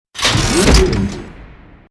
CHQ_FACT_door_unlock.mp3